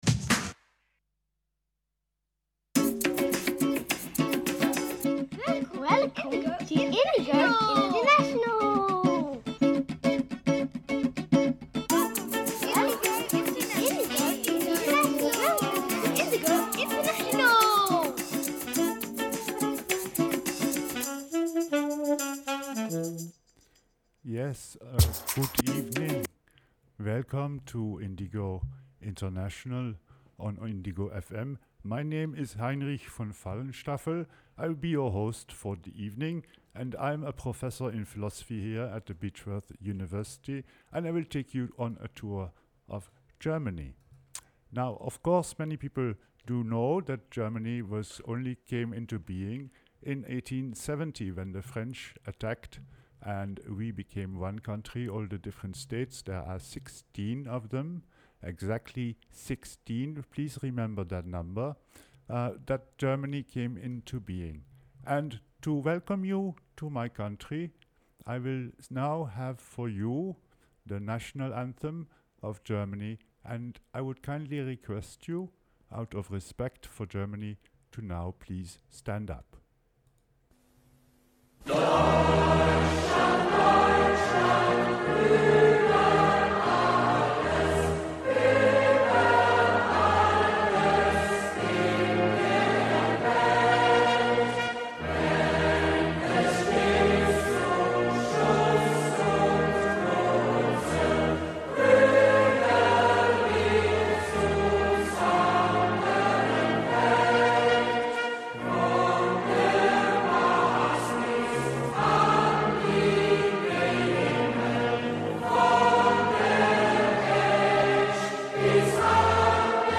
The last episodes of Indigo International, my weekly musical comedy show on Indigo FM.